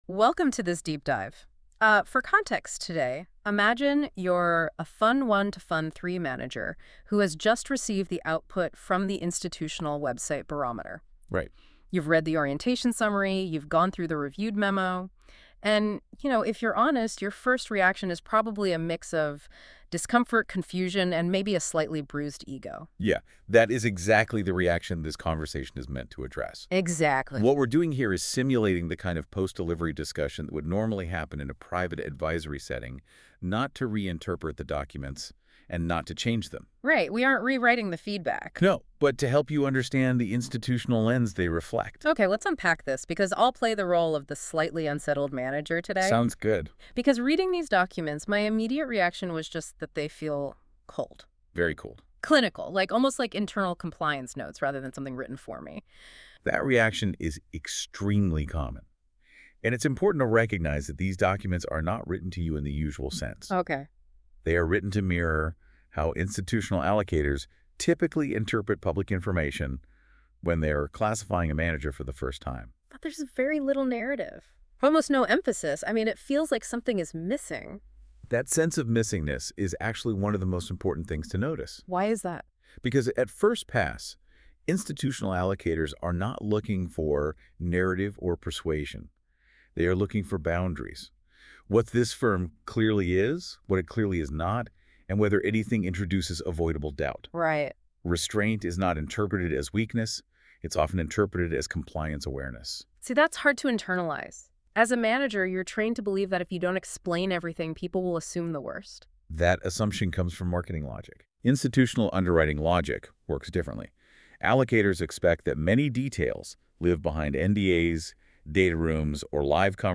Optional: The short audio below simulates a post-delivery debrief, offering context on how institutional allocators typically interpret fund websites and why the Barometer outputs are written in a restrained, allocator-style tone.